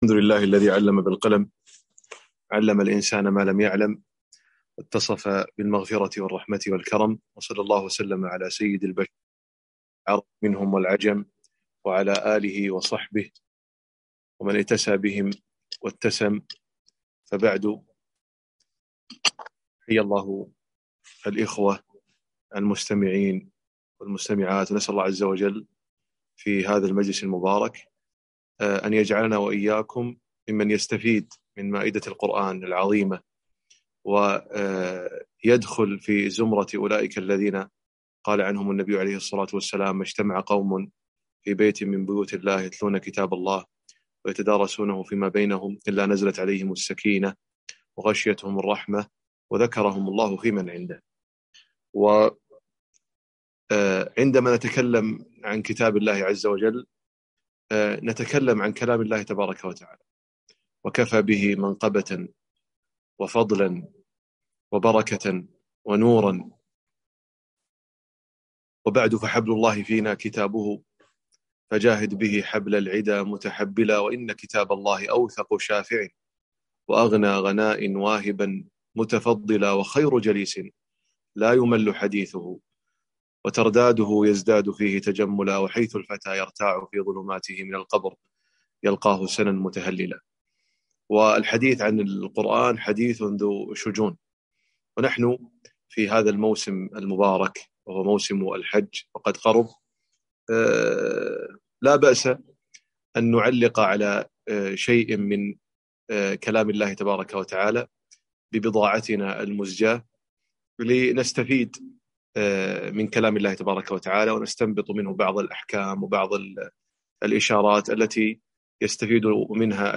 محاضرة - وقفات مع أيات الحج